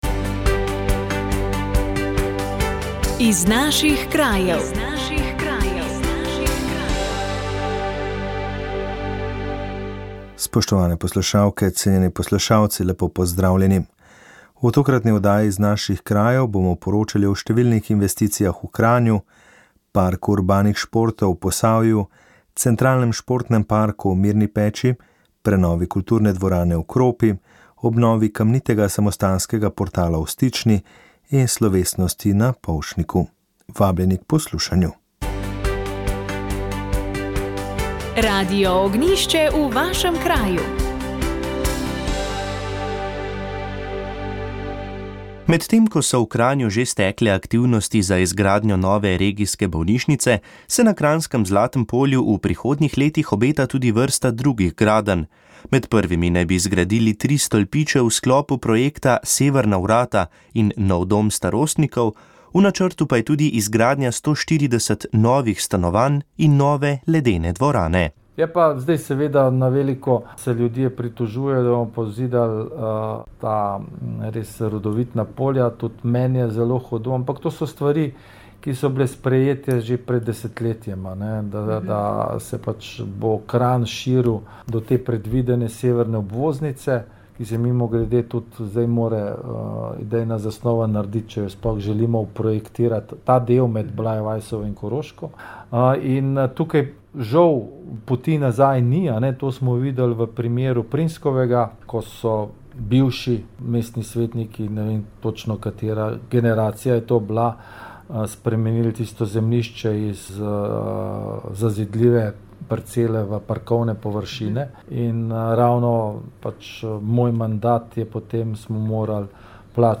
Molile so redovnice - usmiljenke.